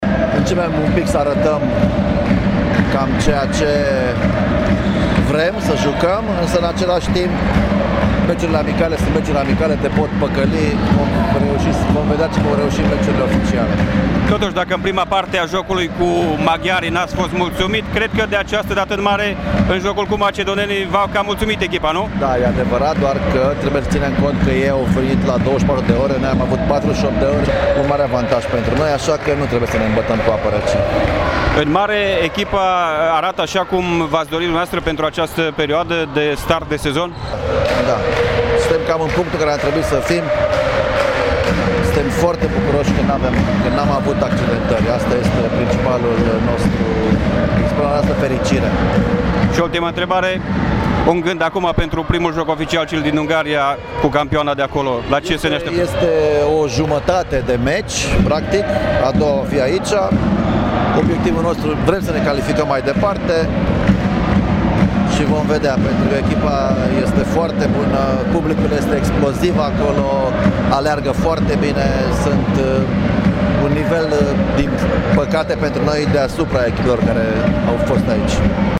la finalul turneului de pregătire